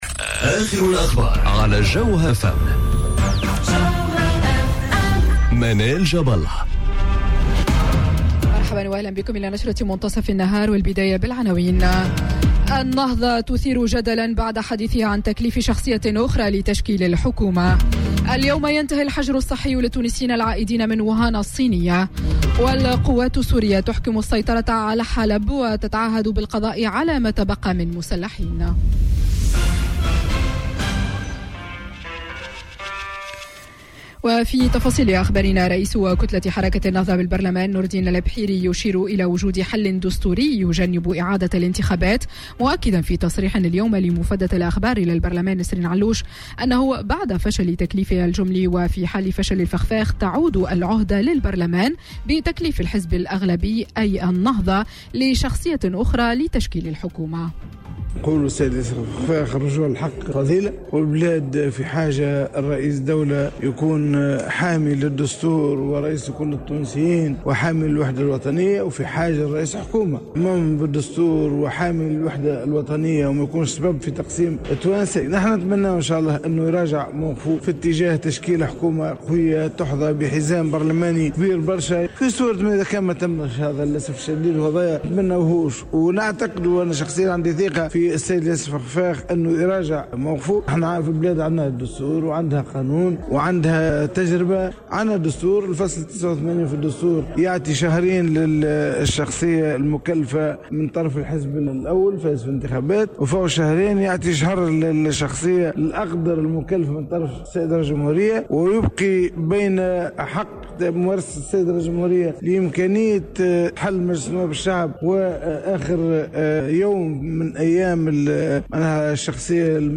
نشرة أخبار منتصف النهار ليوم الإثنين 17 فيفري 2020